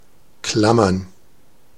Ääntäminen
IPA: /a.kʁɔ.ʃe/